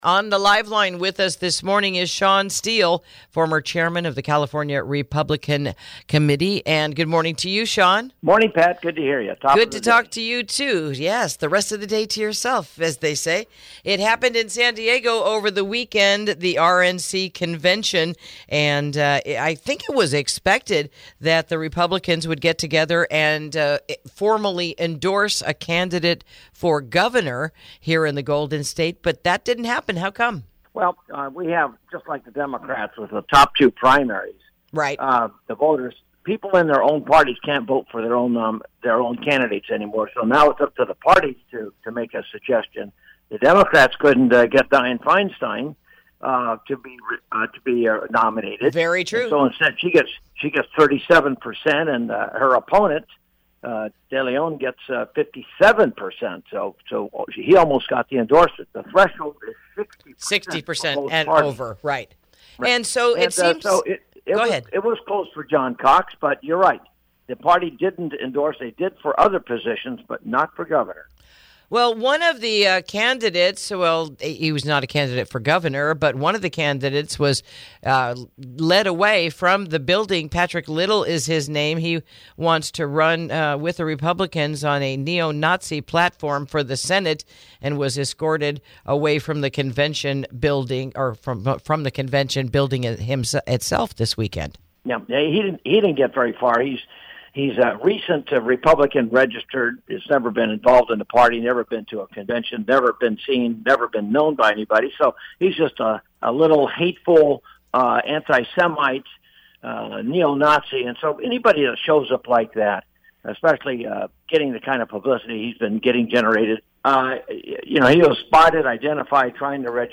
Interview: Repealing Gas Tax, Sanctuary City Status Addressed at California Republican Convention
Former California RNC Chairman Shawn Steel at the California Republican Convention in San Diego, shares why they didn’t support a singular Republican candidate for the governorship, why one of the candidates was escorted from the convention center during the festivities, and the attempt to repeal the California gas tax and sanctuary city and state status in California: